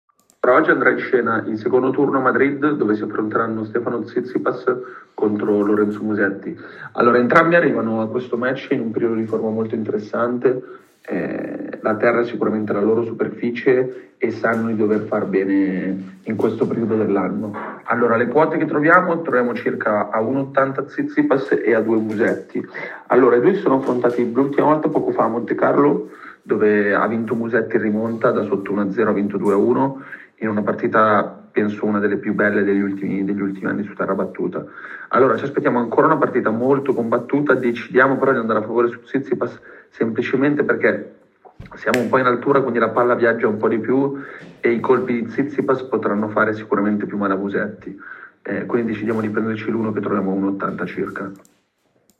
In questa audio analisi